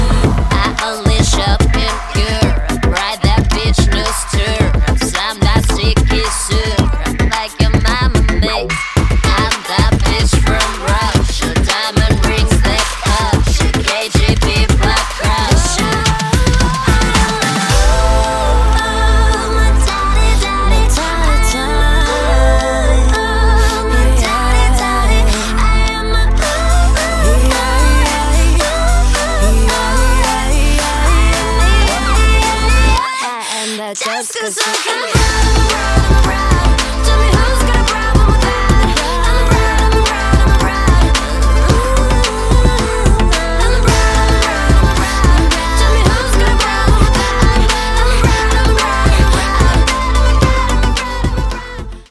поп
женский вокал
заводные